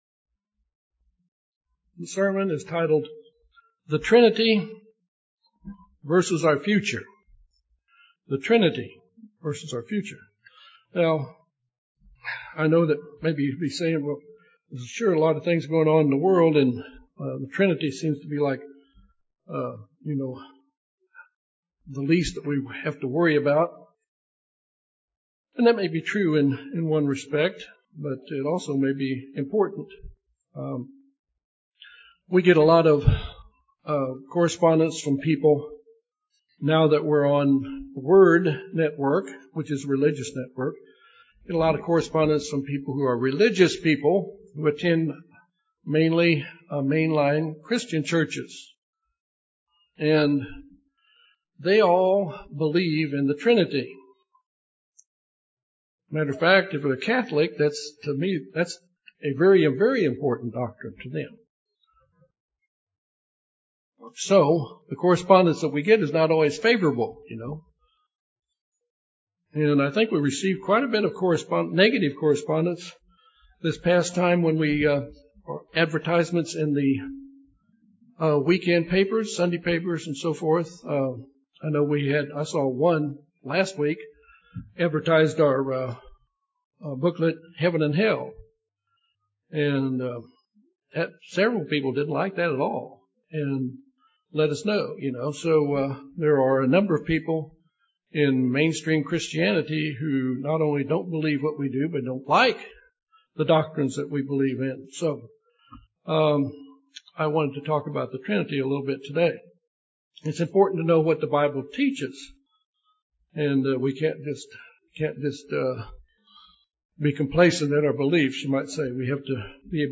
This sermon discusses scriptures used by those who believe the trinity and those who do not. It also explores some of the history of the trinity doctrine, including the influence of Roman emperors Constantine and Theodosius in promoting and enforcing the doctrine throughout the Roman Empire. The last part of this sermon discusses God’s plan to bring many sons and daughters into His family.